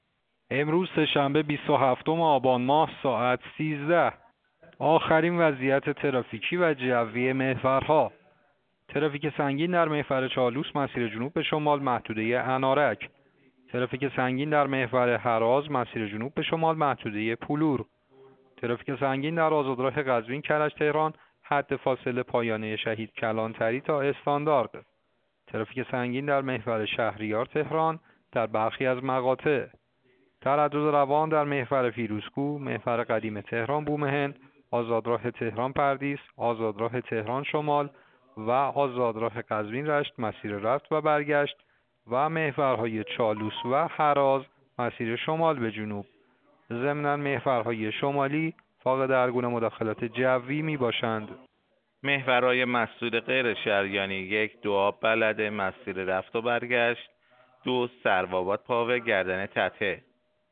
گزارش رادیو اینترنتی از آخرین وضعیت ترافیکی جاده‌ها ساعت ۱۳ بیست و هفتم آبان؛